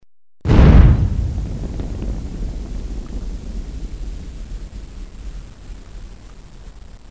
جلوه های صوتی
دانلود صدای بمب و موشک 20 از ساعد نیوز با لینک مستقیم و کیفیت بالا